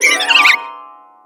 Cri de Roserade dans Pokémon X et Y.